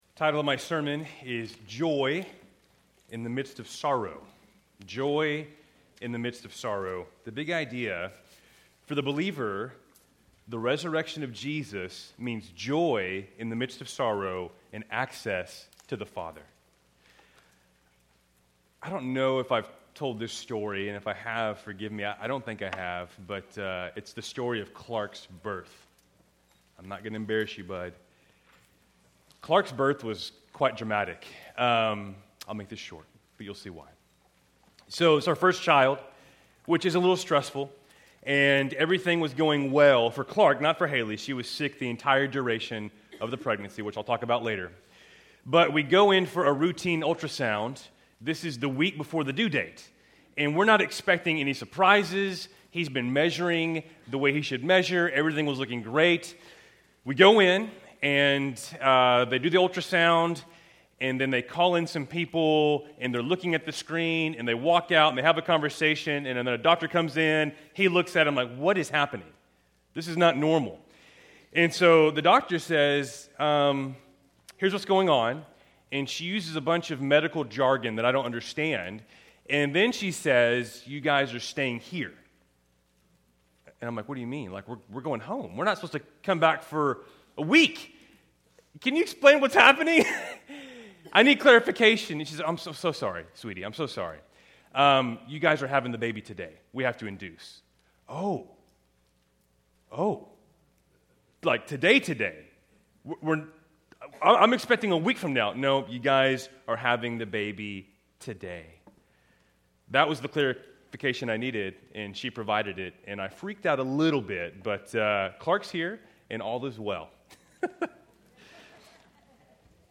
Keltys Worship Service, October 19, 2025